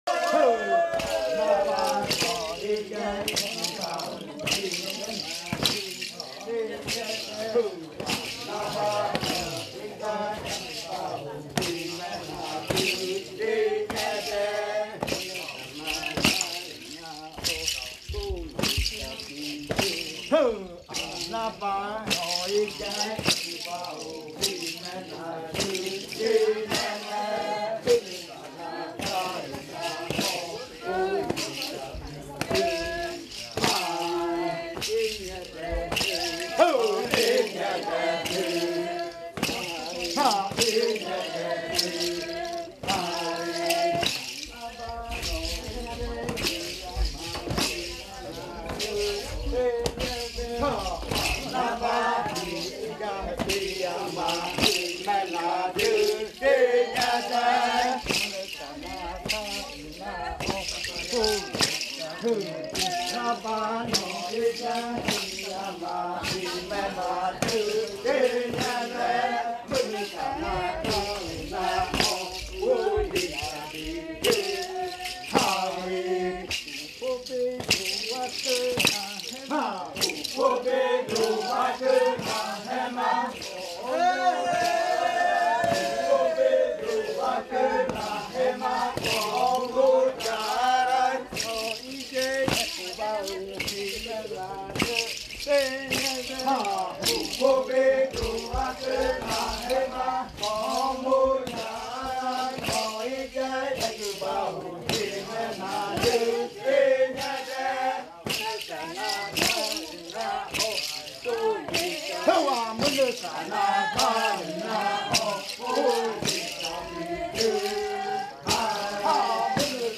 Canto de cierre de la variante muinakɨ
Leticia, Amazonas
with the group of singers dancing at Casa Hija Eetane.